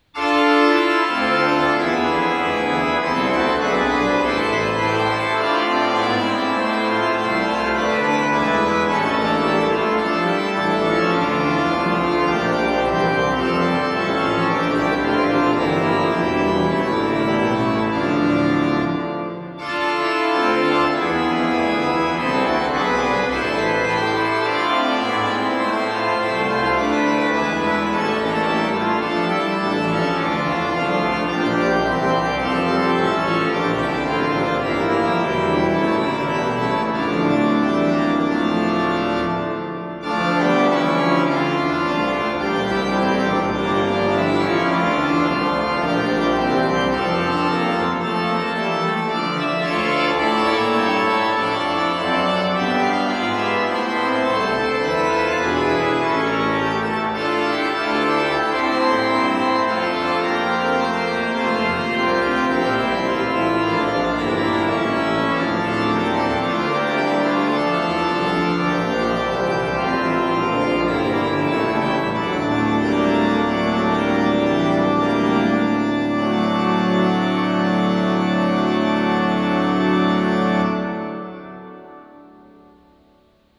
Pfarrkirche St. Gallus Bonn-Küdinghoven – Kirchenmusik zwischen Rhein und Ennert
Die Orgel wurde 1981 durch die Firma Johannes Klais Bonn erbaut. Sie besitzt zwei Manuale und 25 Register, eine mechanische Spiel- und eine elektrische Registertraktur.
Orgelmusik aus St. Gallus Die Orgel wurde 1981 durch die Firma Johannes Klais Bonn erbaut.